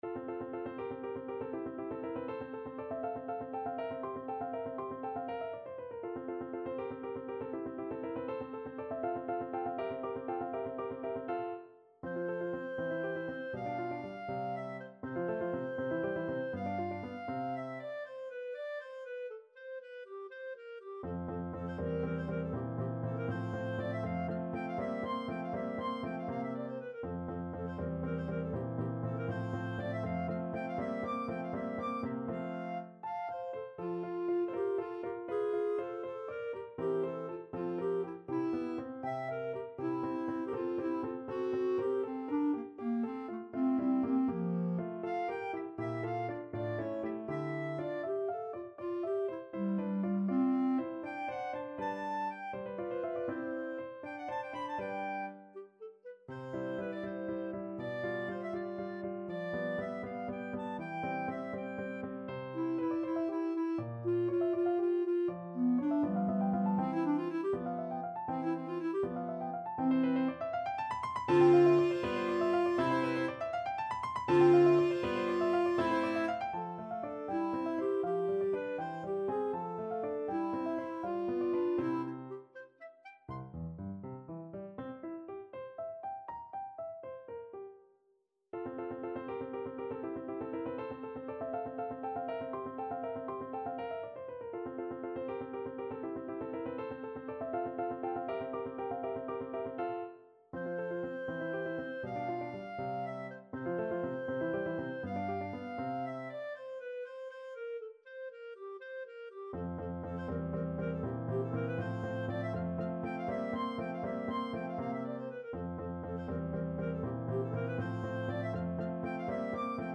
6/8 (View more 6/8 Music)
~ = 100 Allegro (View more music marked Allegro)